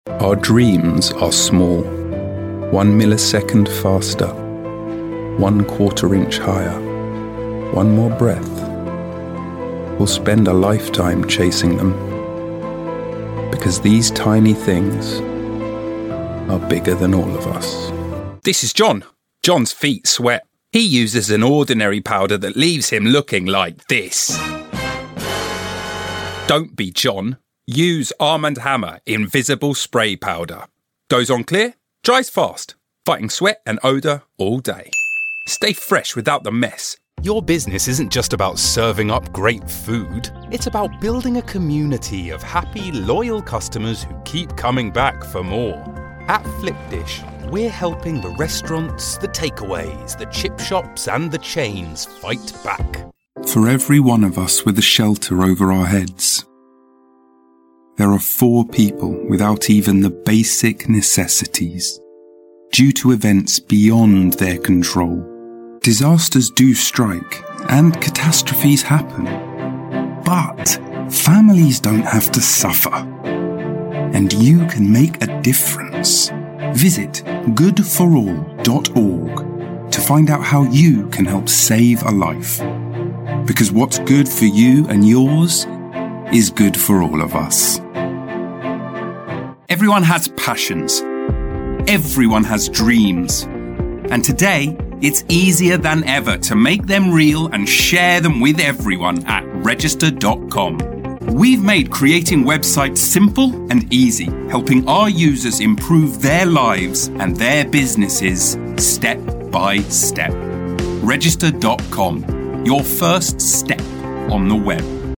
Categories: Announcer , Articulate , International Voices , Male , Versatile Tag: British